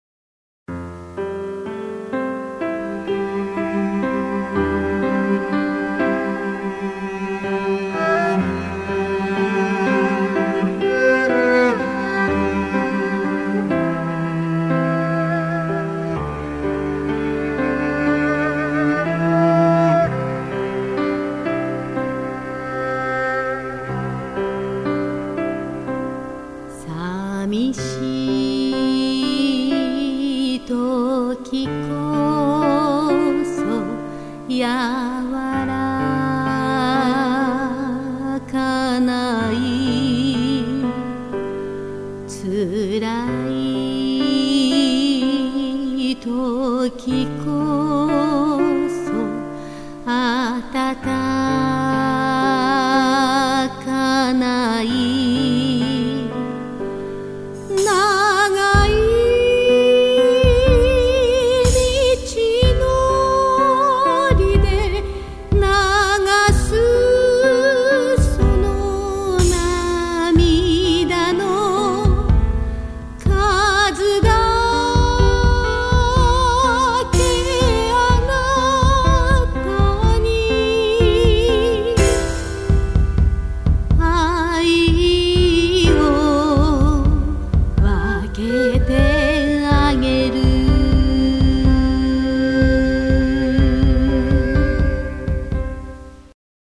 その音楽とは「現代民族歌謡」といいます。